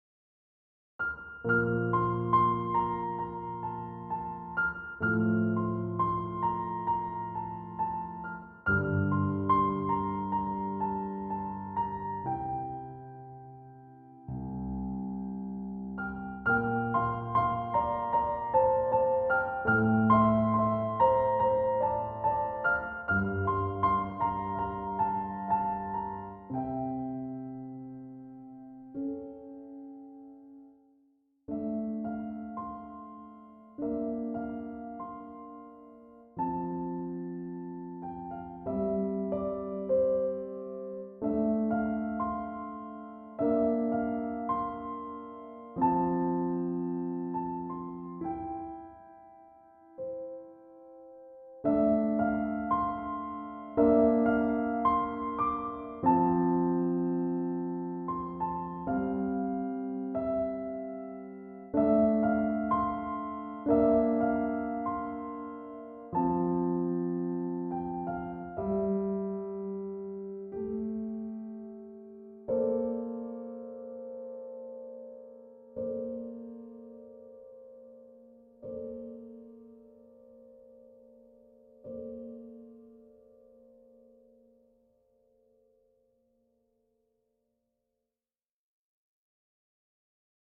This is the piano only version, but I'm working on a more fleshed-out version as well.